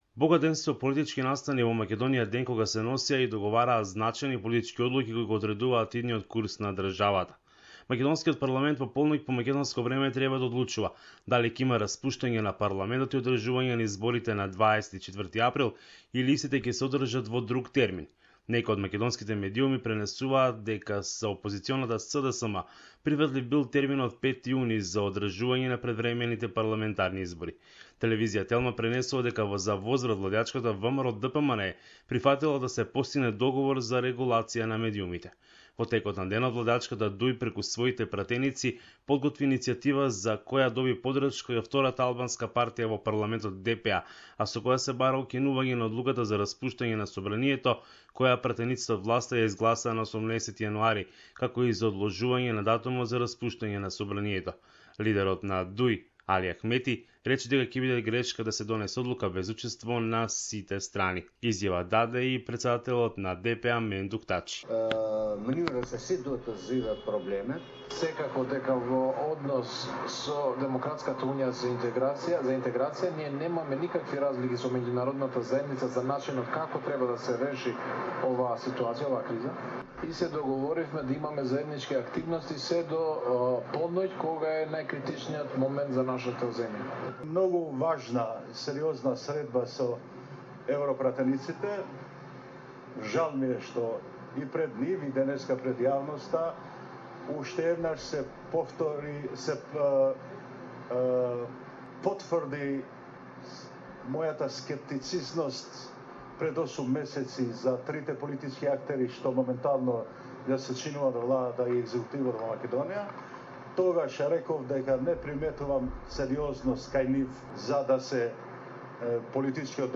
Macedonia Report